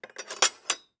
SFX_Cooking_Knife_PutDown_01.wav